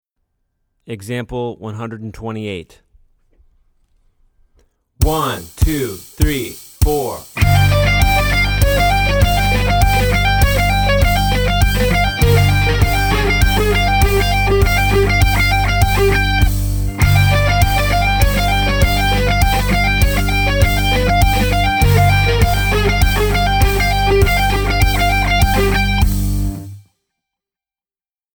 Voicing: Guitar/CD